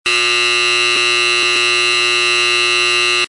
Loud Buzzer Sound Button - Free Download & Play
The Loud Buzzer sound button is a popular audio clip perfect for your soundboard, content creation, and entertainment.